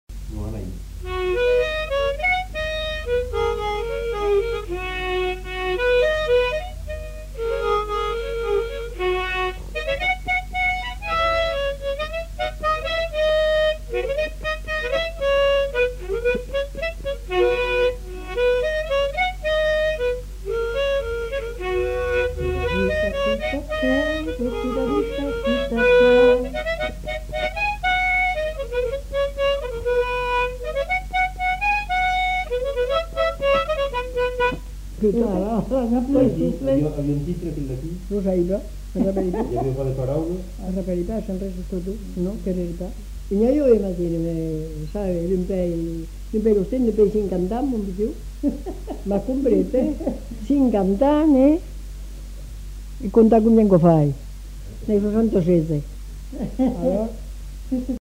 Aire culturelle : Haut-Agenais
Genre : morceau instrumental
Instrument de musique : harmonica
Danse : rondeau